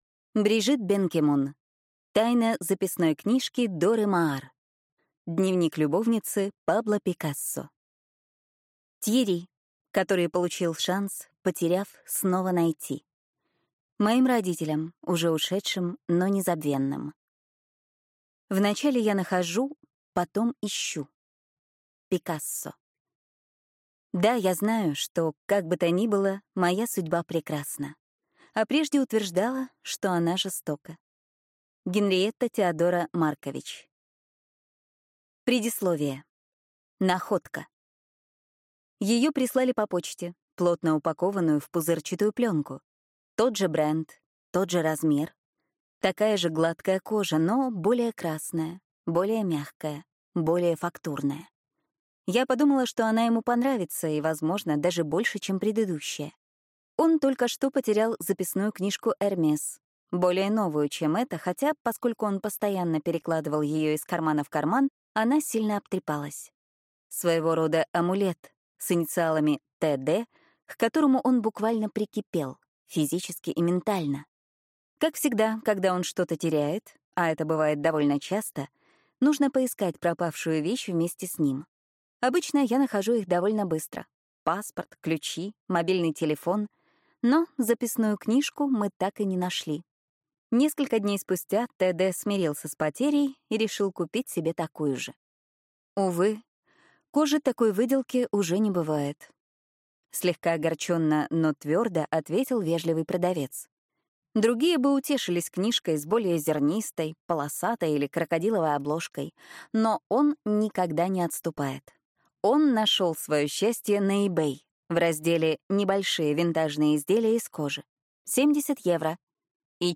Аудиокнига Тайна записной книжки Доры Маар. Дневник любовницы Пабло Пикассо | Библиотека аудиокниг